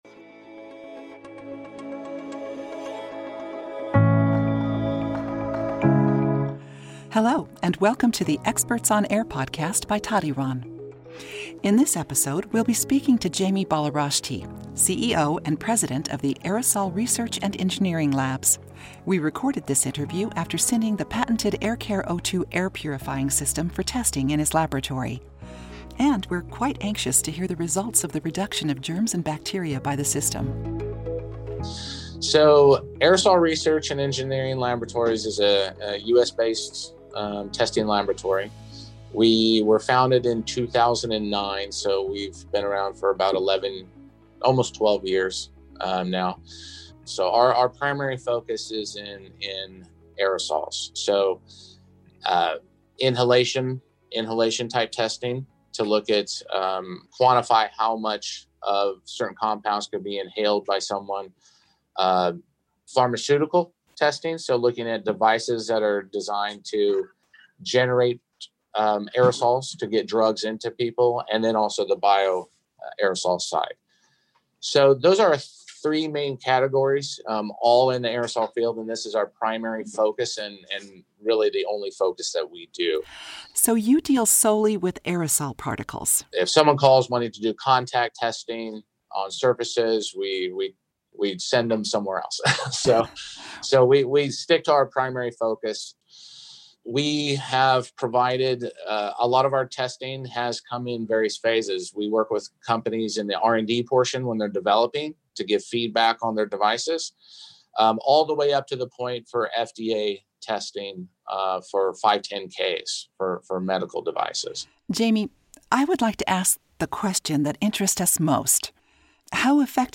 Listen to the experts talk about the Aircarevolution